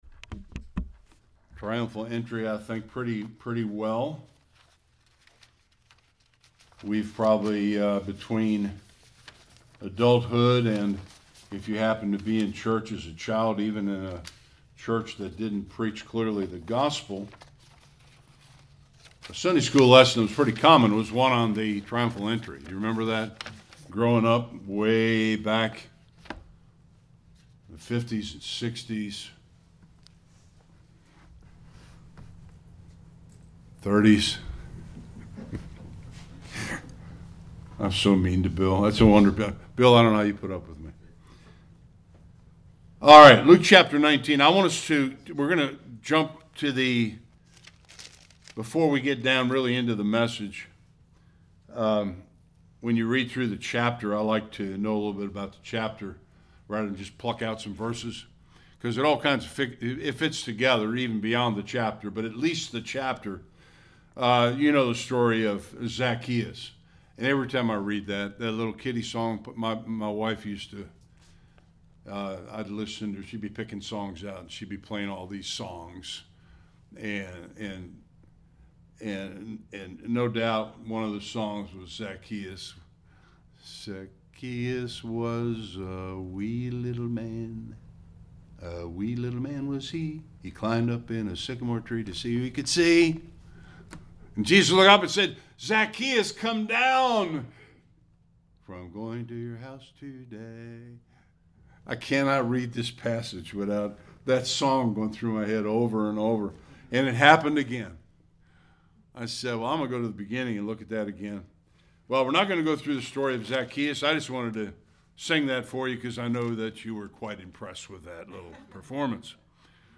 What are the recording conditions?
41-44 Service Type: Sunday Worship Was the Triumphal entry into Jerusalem a time of triumph or tragedy?